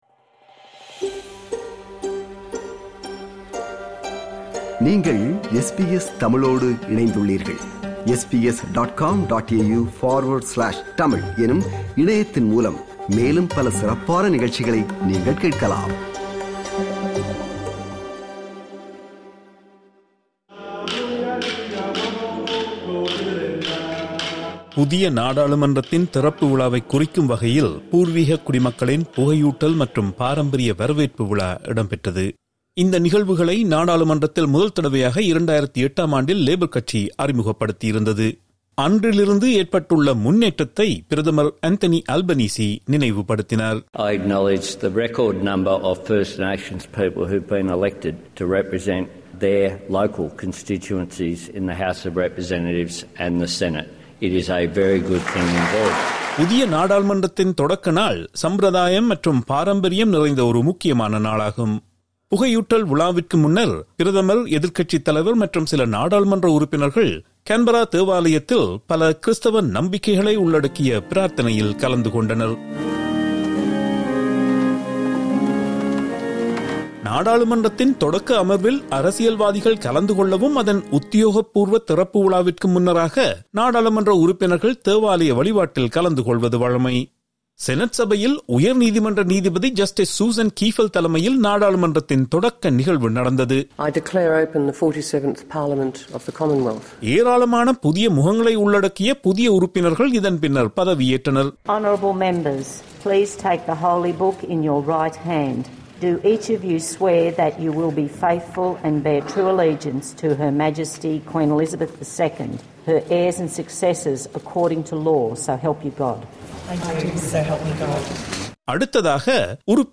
reports in Tamil with a feature